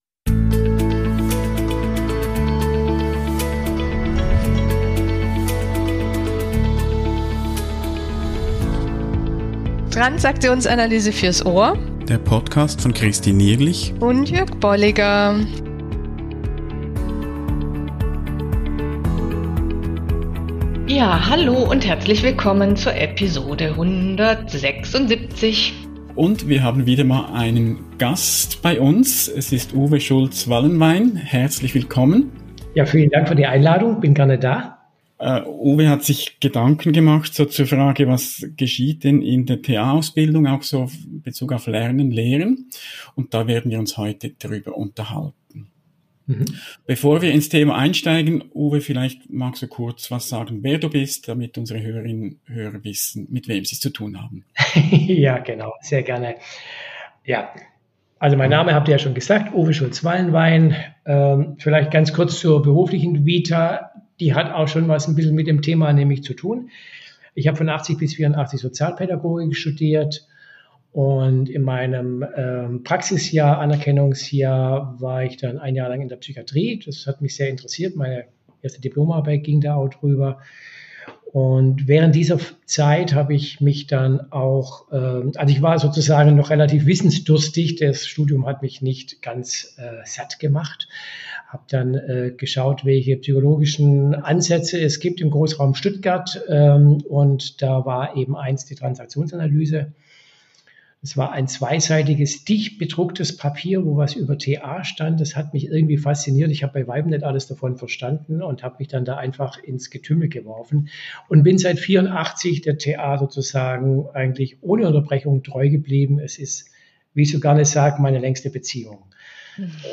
Gespräche über Modelle und Konzepte der Transaktionsanalyse und deren Anwendung